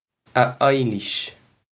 Pronunciation for how to say
Ah Eye-leesh
This is an approximate phonetic pronunciation of the phrase.